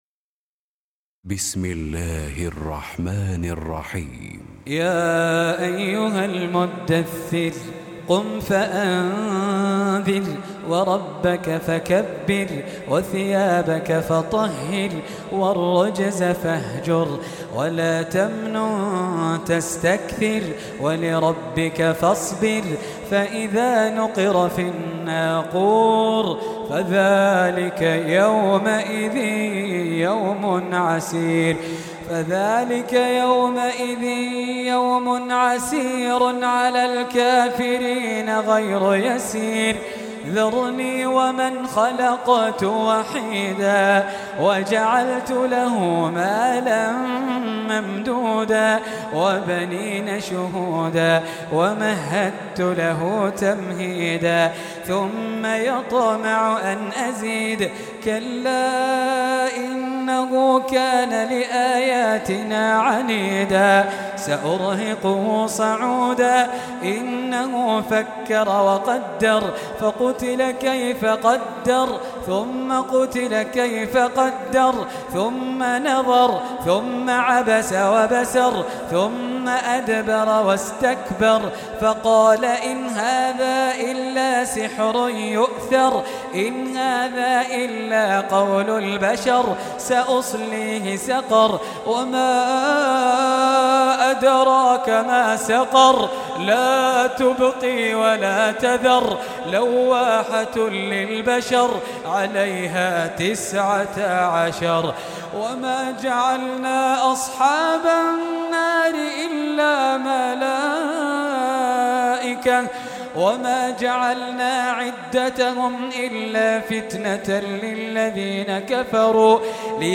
ترتیل قرآن